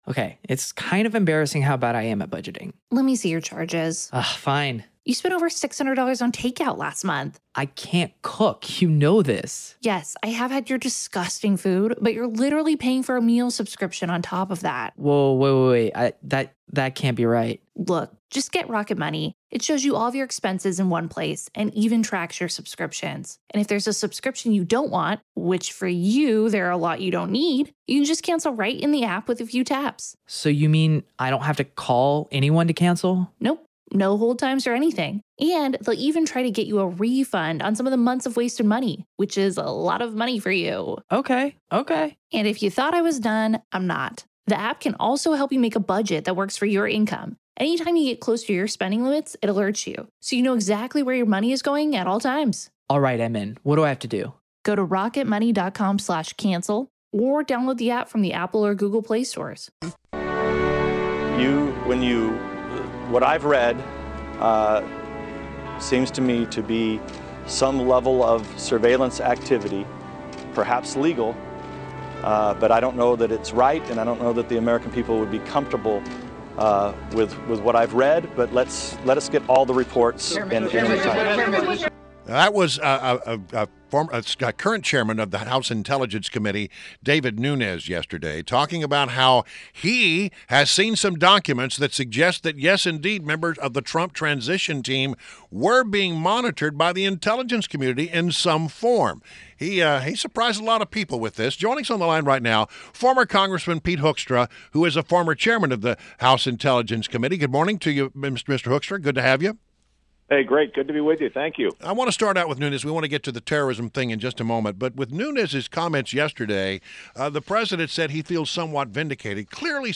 WMAL Interview - REP PETE HOEKSTRA - 03.23.17
INTERVIEW - Former Congressman PETE HOEKSTRA - (hook stra) - is the former Chairman of the U.S. House Intelligence Committee • TOPICS: Discuss the House Intel Chair's announcement about Trump's communications possibly collected and his thoughts on the London attack